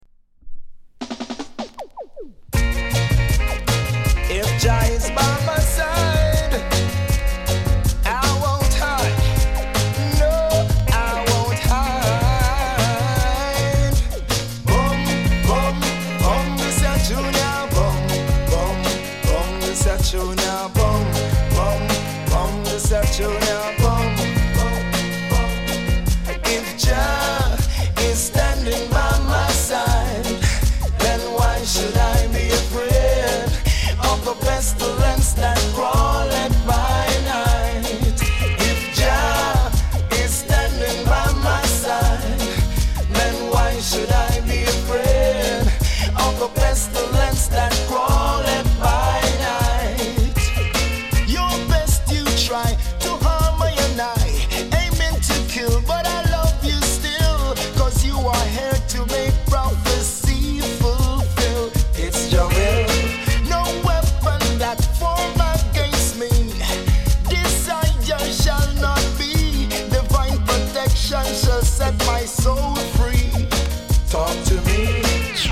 category Reggae